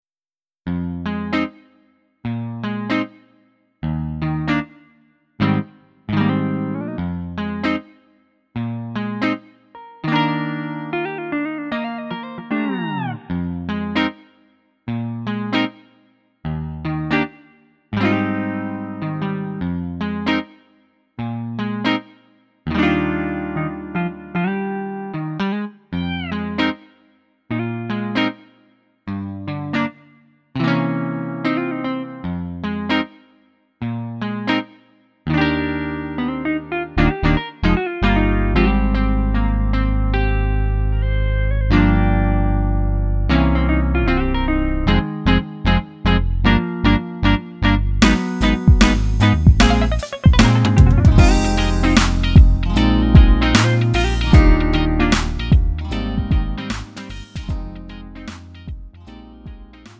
음정 -1키 3:53
장르 구분 Lite MR